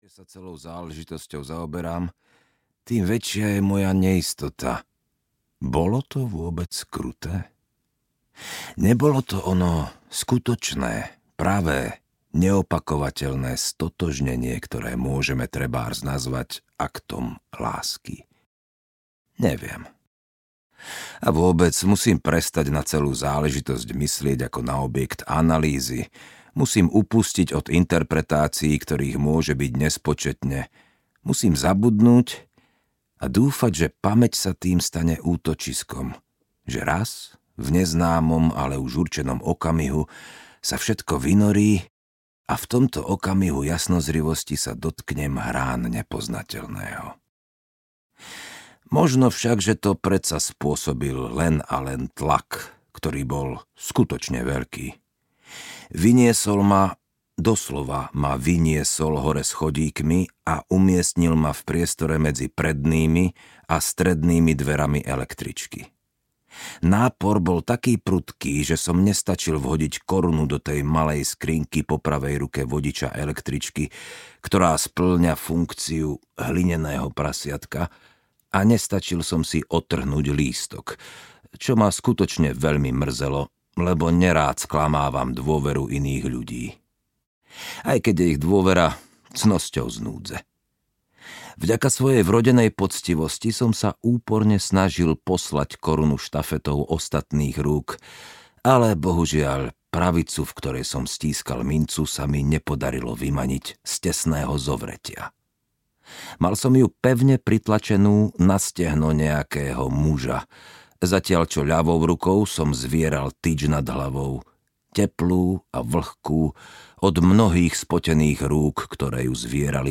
Iná láska audiokniha
Ukázka z knihy